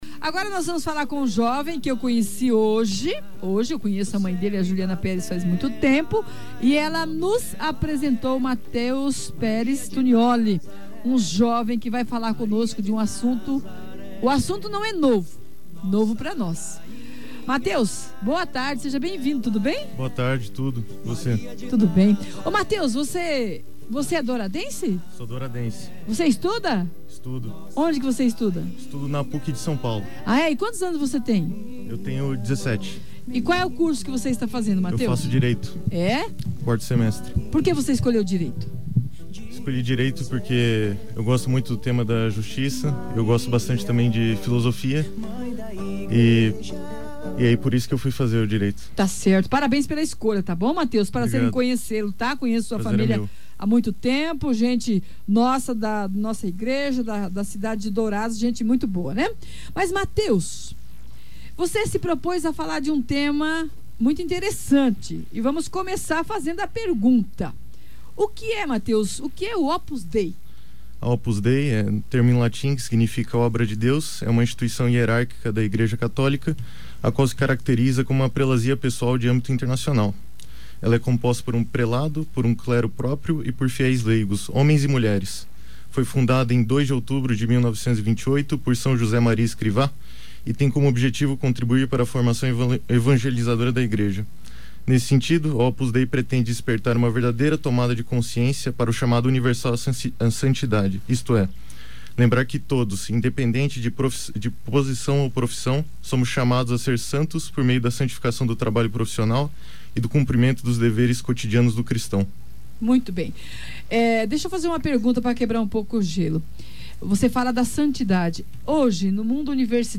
Jovem apresenta Opus Dei na Rádio Coração